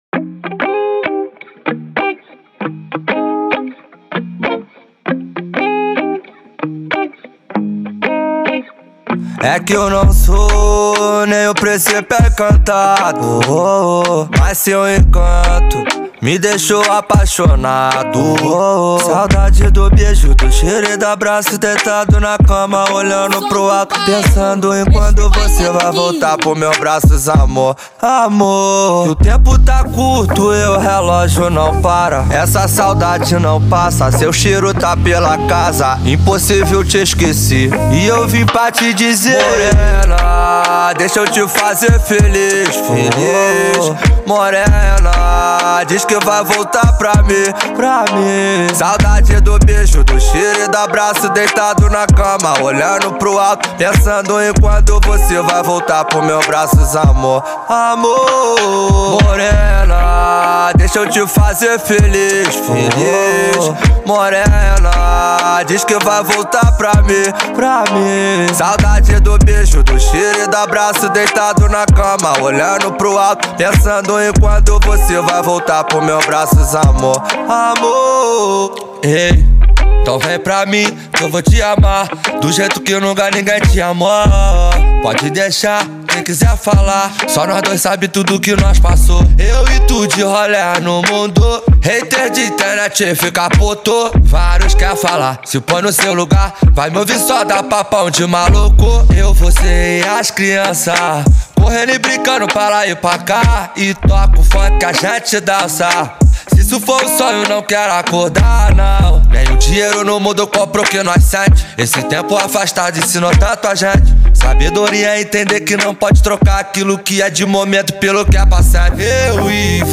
Genero: RAP